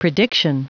Prononciation du mot prediction en anglais (fichier audio)
Prononciation du mot : prediction